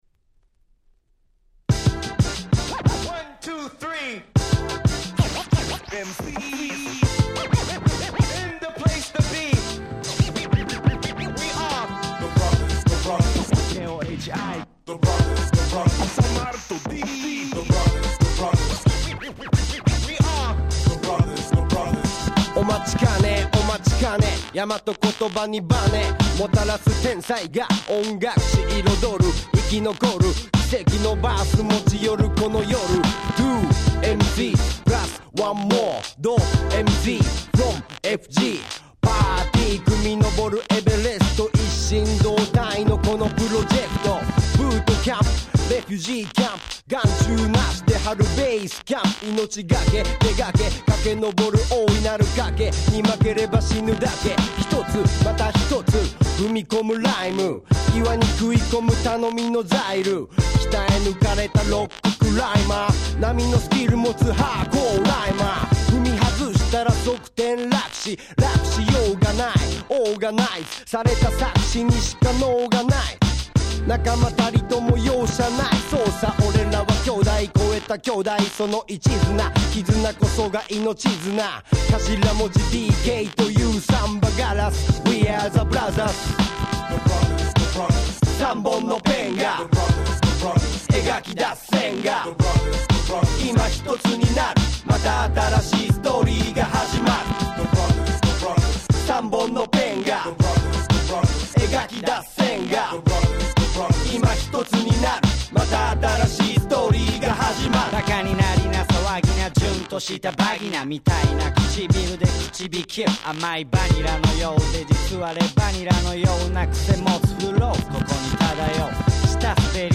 (視聴ファイルは別の盤から録音してございます。)
99' Japanese Hip Hop Classic !!